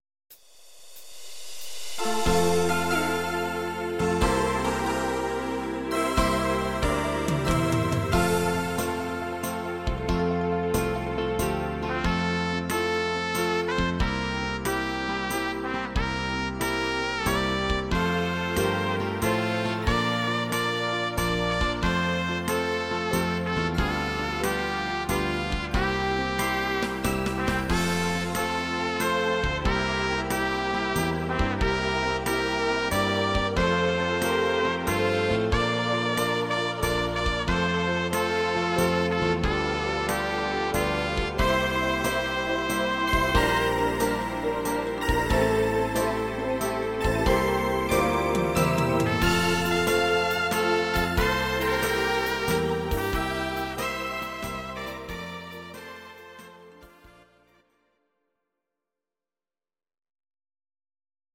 Audio Recordings based on Midi-files
German, 1970s